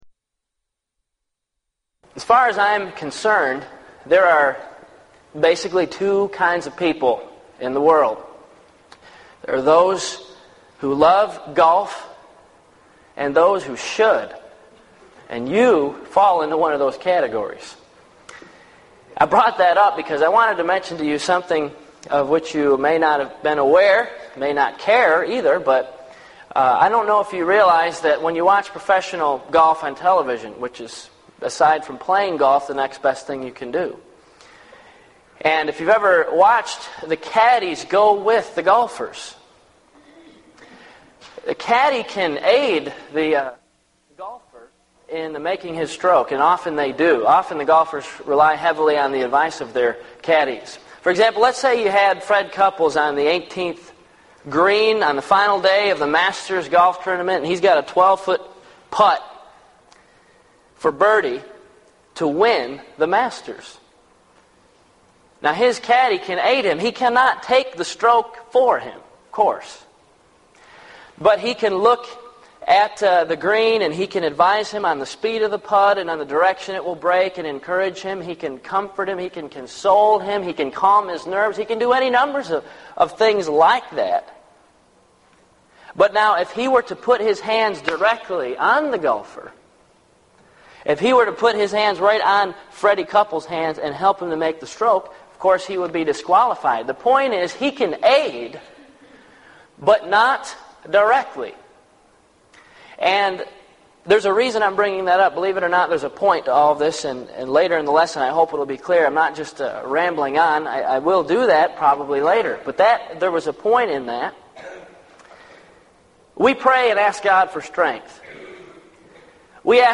Event: 1998 Gulf Coast Lectures Theme/Title: Prayer and Providence
lecture